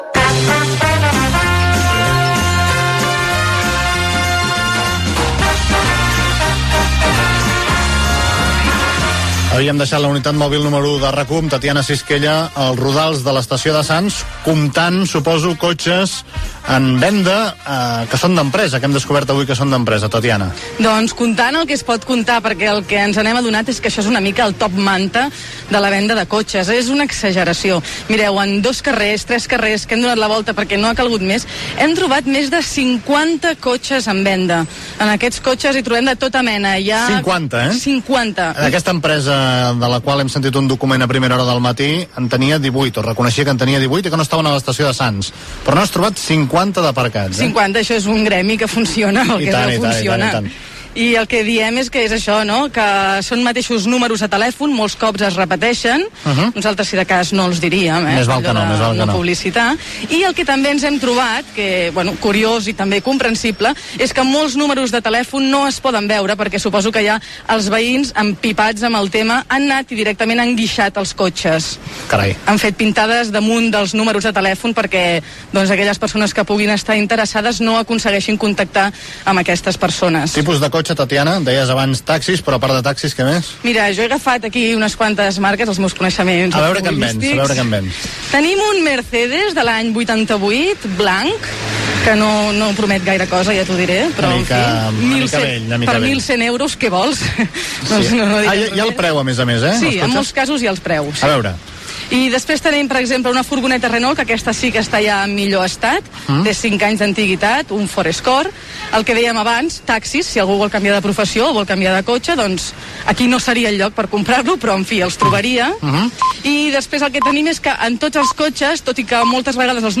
Connexió amb la unitat mòbil de l'emissora que està a la zona de l'Estació de Sants per informar dels cotxes aparcats al carrer que estan en venda. Secció de televisió, amb la primera col·laboració de Sergi Pàmies a RAC 1
Info-entreteniment